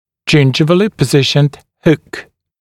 [‘ʤɪnʤɪvəlɪ pə’zɪʃ(ə)nd huk][‘джиндживэли пэ’зиш(э)нд хук]расположенный с десневой стороны крючок